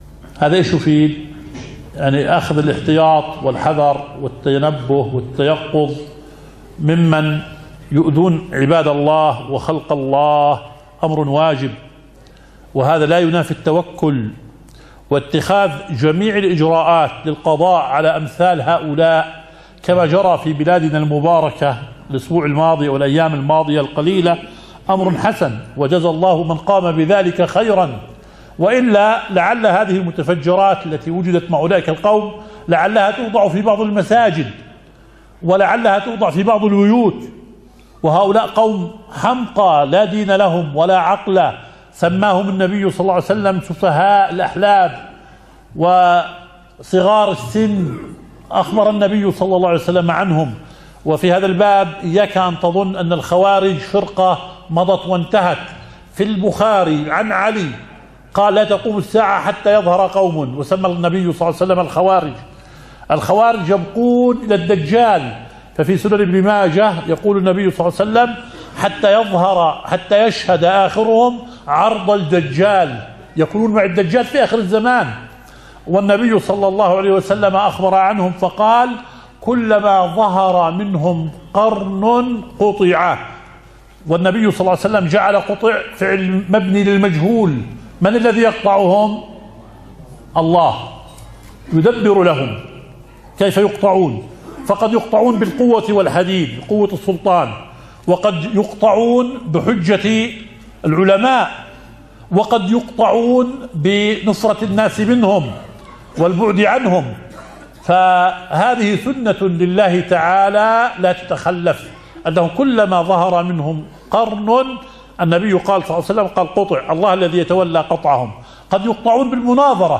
شرح صحيح مسلم